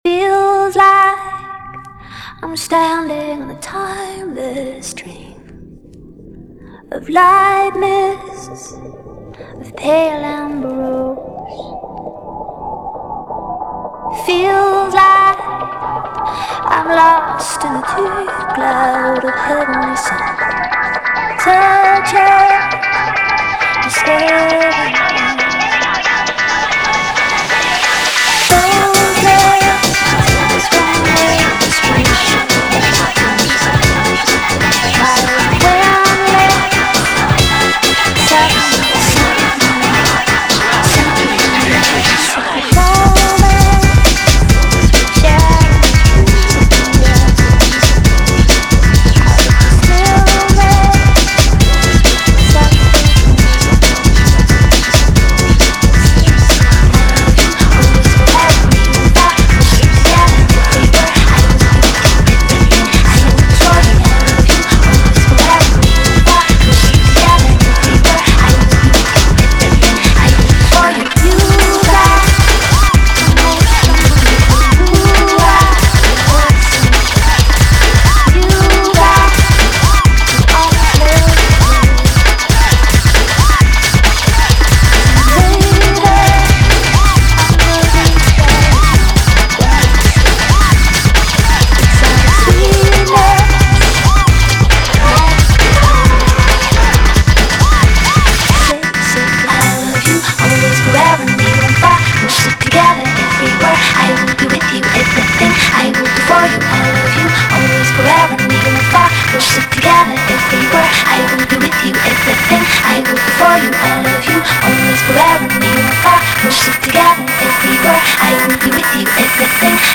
Music / Techno
footwork breakwork dance mashup mashcore dancemusic